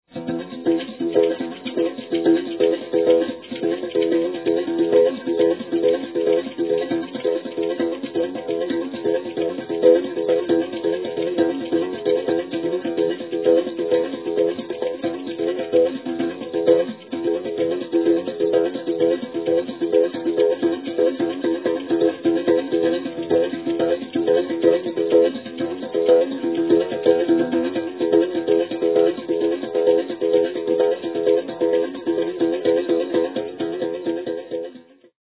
traditional music of the Capoeira
plays the berimbau